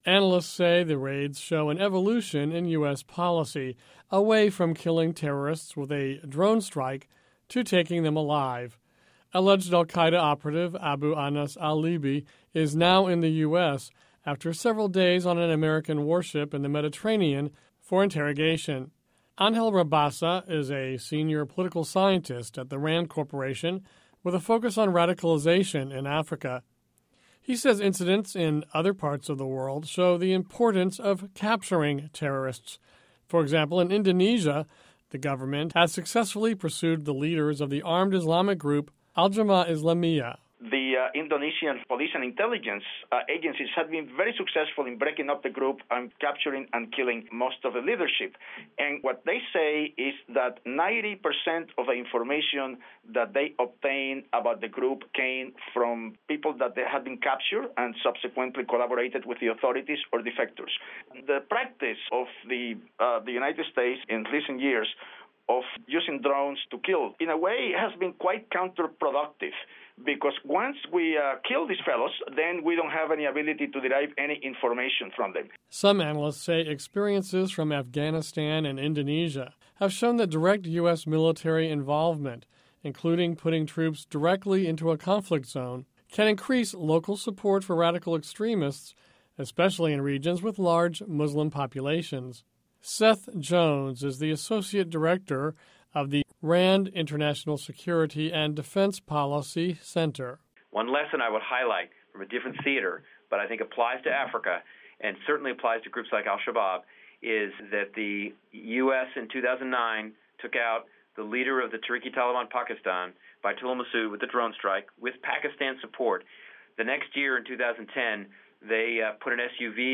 RAND analysts comment on US terrorism strategies